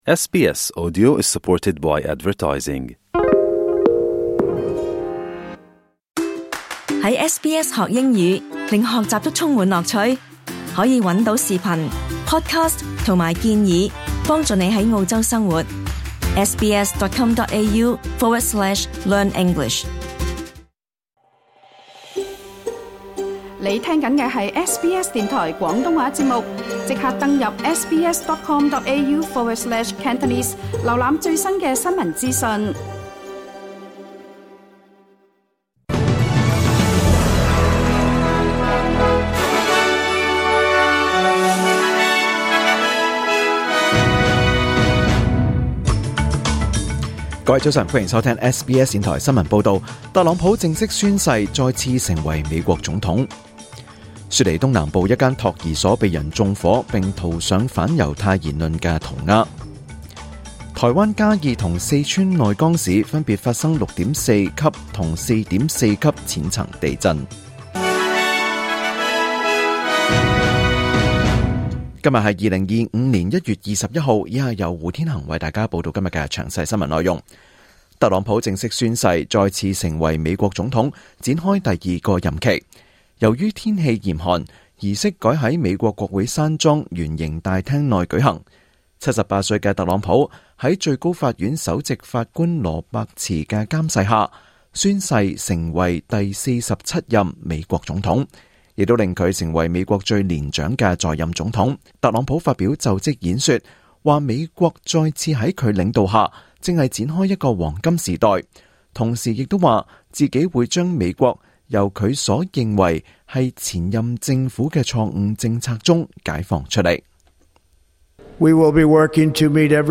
2025 年 1 月 21 日 SBS 廣東話節目詳盡早晨新聞報道。